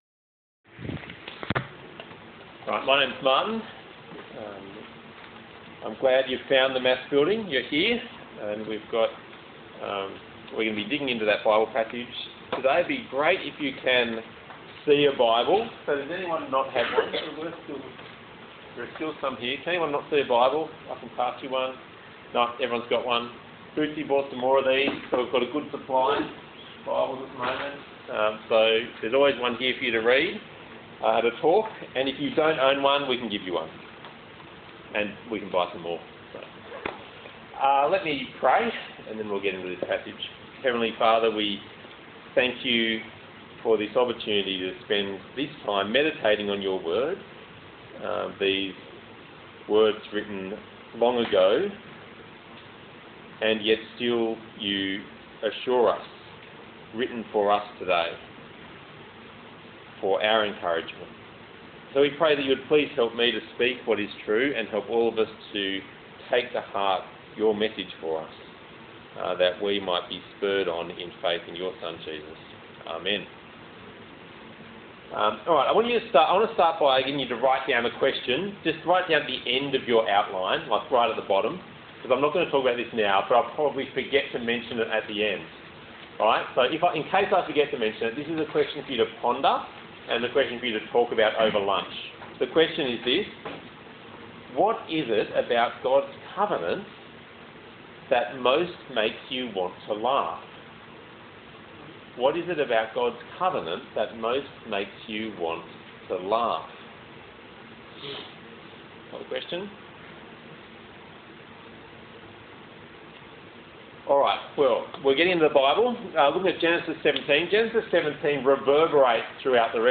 Passage: Genesis 17 Talk Type: Bible Talk